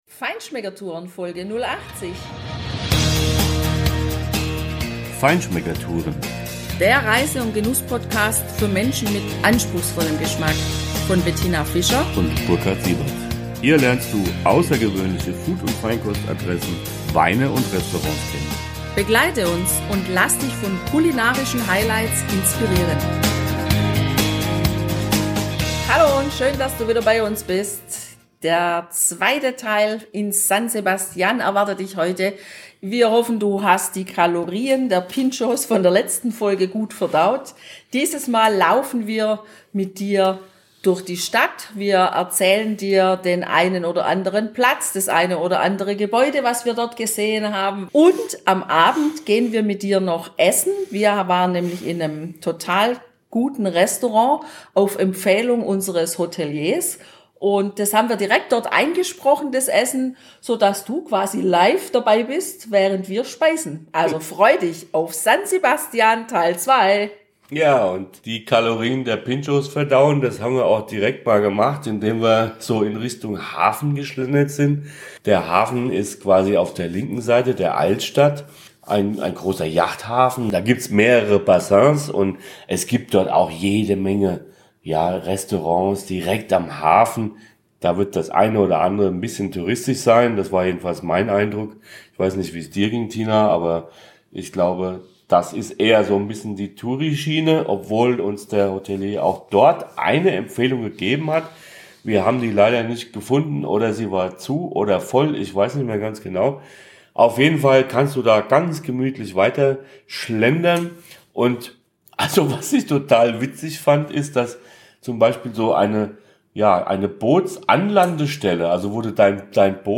Du bist live dabei, wenn wir im Restaurant Gandarias unser Menü mit Gambas der besonderen Art sowie einen schönen Rosé-Wein von Muga besprechen!